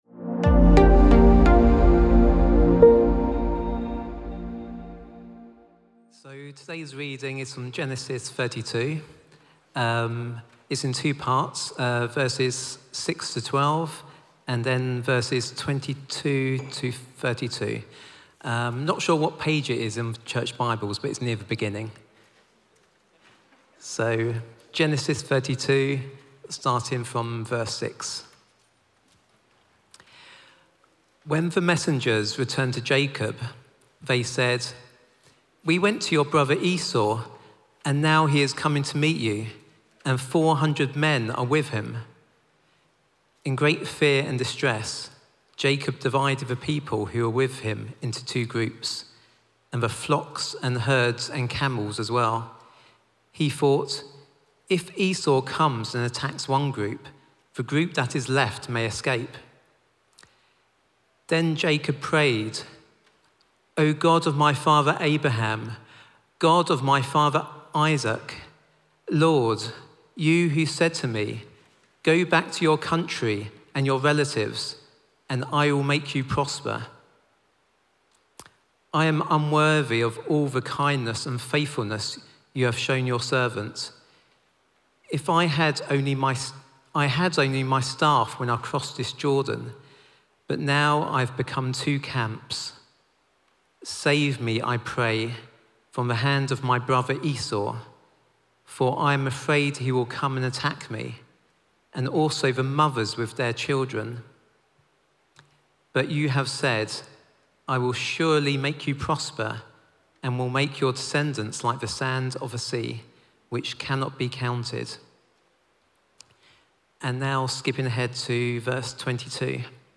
The Evening Sermon 13.07.25 - All Saints Peckham